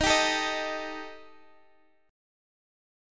EbMb5 chord